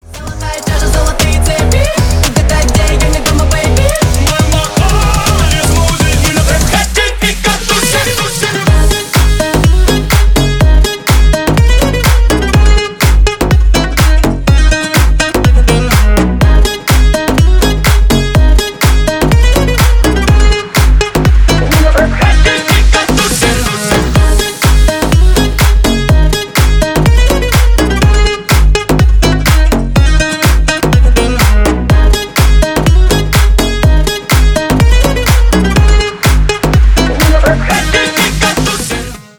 Ремикс
Танцевальные